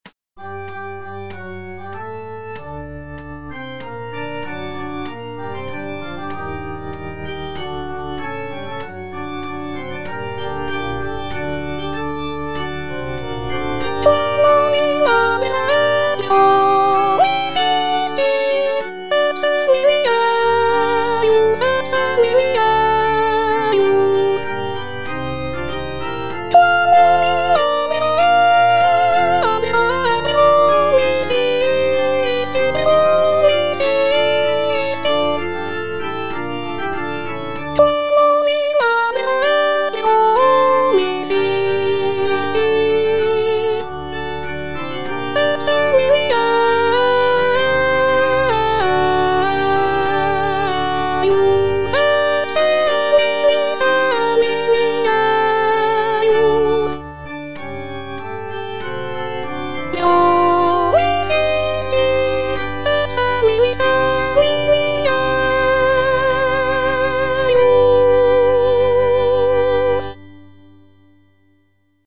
M. HAYDN - REQUIEM IN C (MISSA PRO DEFUNCTO ARCHIEPISCOPO SIGISMUNDO) MH155 Quam olim Abrahae - Soprano (Voice with metronome) Ads stop:
The work is characterized by its somber and mournful tone, reflecting the solemnity of a funeral mass.